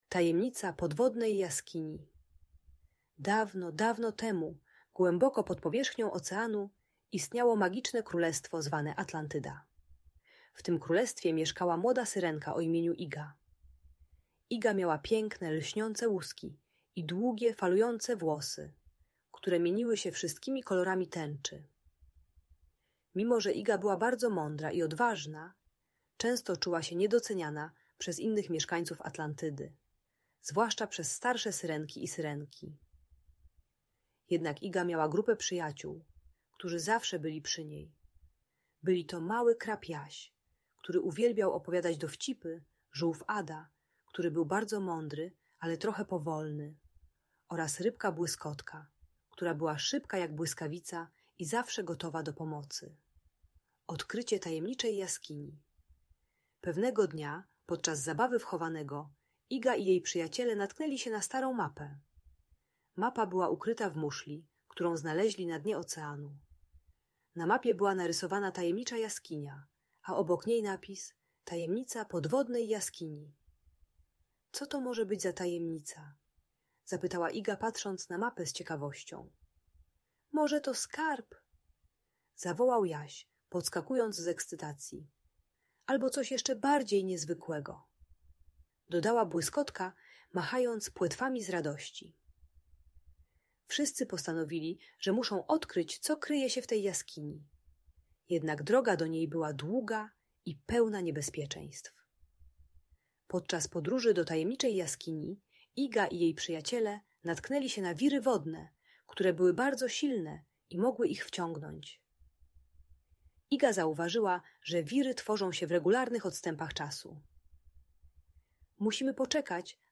Tajemnicza Podwodna Jaskinia - Audiobajka dla dzieci